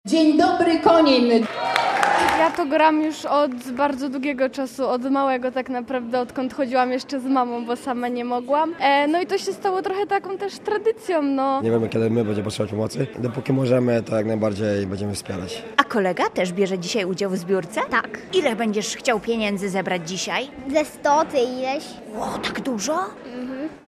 Wszyscy byli w dobrych nastrojach, a chłód nie był im straszny.